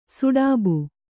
♪ suḍābu